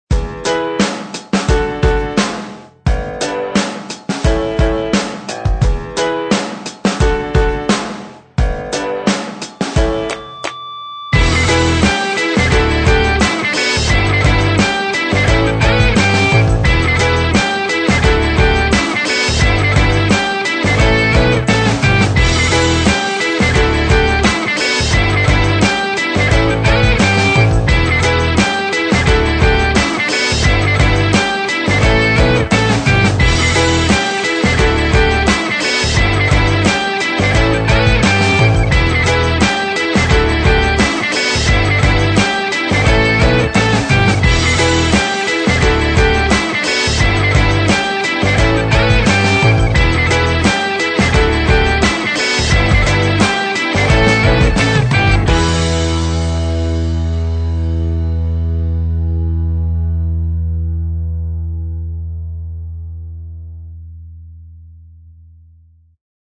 描述：复古音调和朗朗上口的即兴重复，使得这首器乐成为任何乐观生产的绝佳选择。